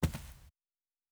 Footstep Carpet Walking 1_06.wav